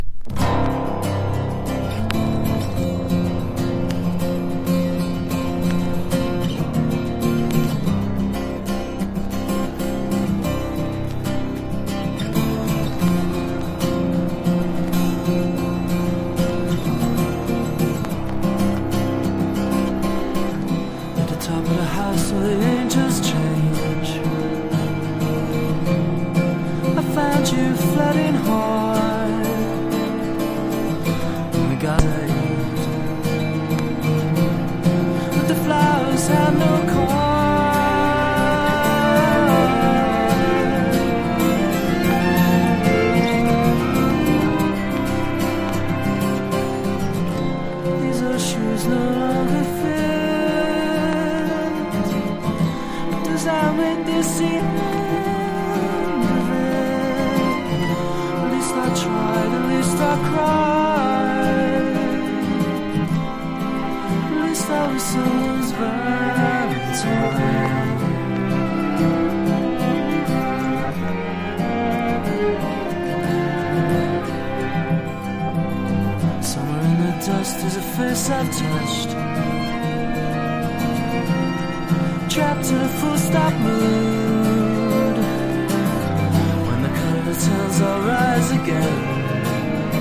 1. 90'S ROCK >
優しい穏やかなサウンドで落ち着きます。
NEO ACOUSTIC / GUITAR POP (90-20’s)